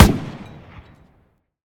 tank-mg-shot-6.ogg